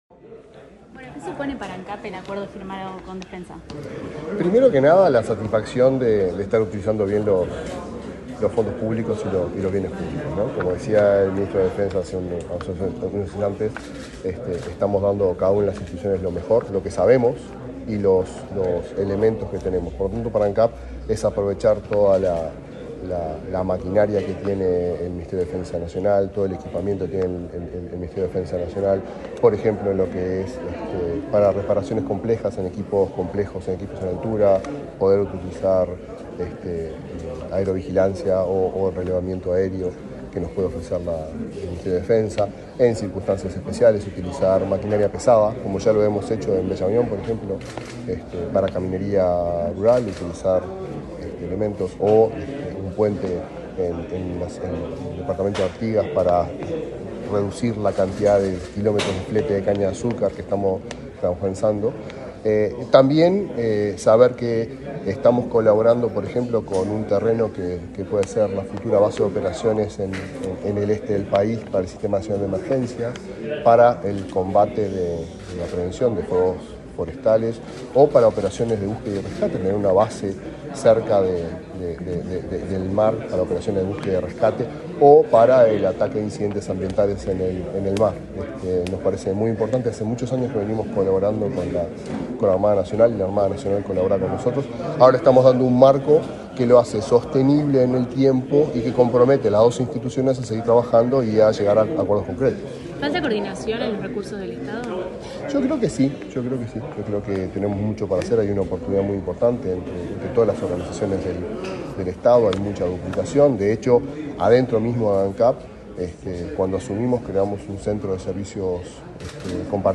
Declaraciones a la prensa del presidente de Ancap, Alejandro Stipanicic
Tras el evento, el presidente de la empresa estatal realizó declaraciones a la prensa.